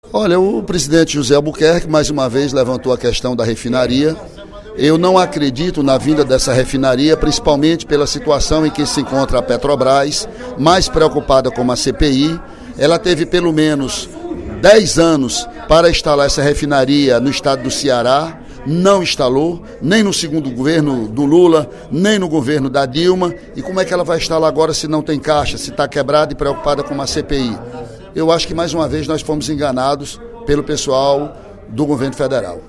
Durante o primeiro expediente da sessão plenária desta terça-feira (15/04), o deputado Ely Aguiar (PSDC) elogiou o presidente da Casa, Zezinho Albuquerque (Pros), ao defender a vinda da refinaria para o Ceará, na abertura dos trabalhos, mas teceu críticas à Petrobras.